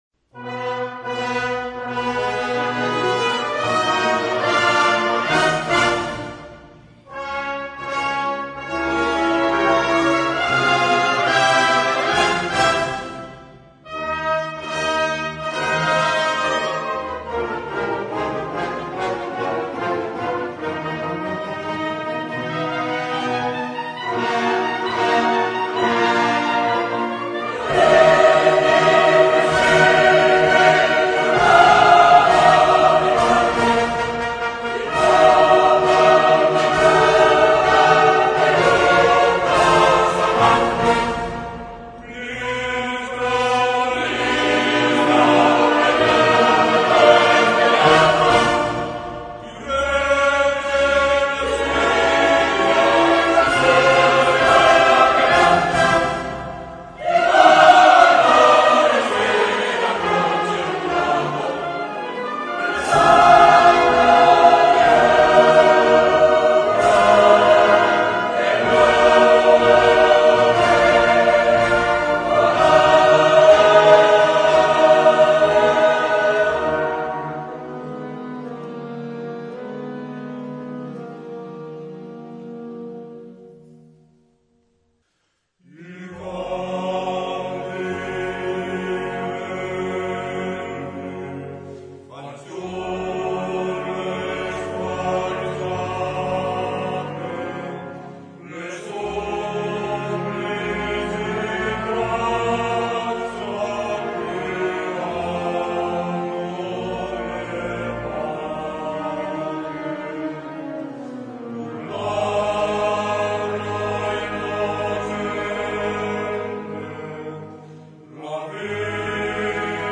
per coro (ad lib) e banda
per coro (ad lib.) e banda.